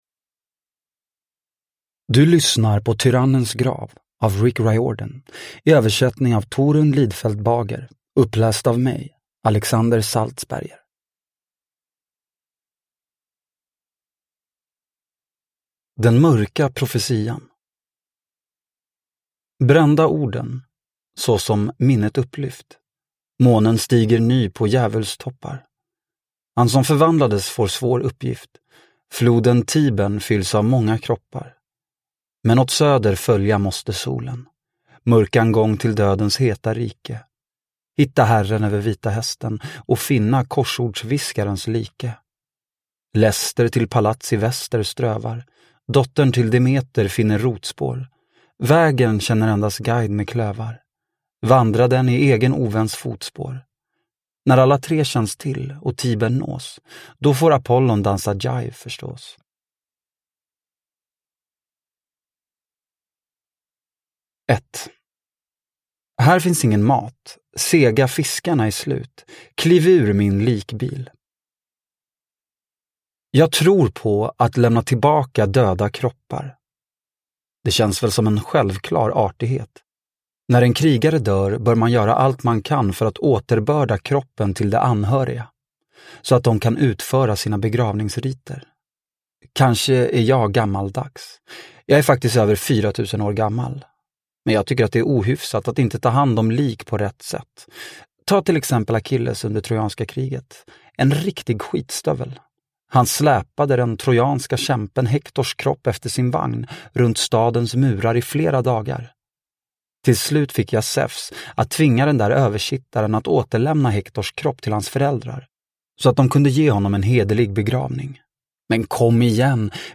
Tyrannens grav – Ljudbok – Laddas ner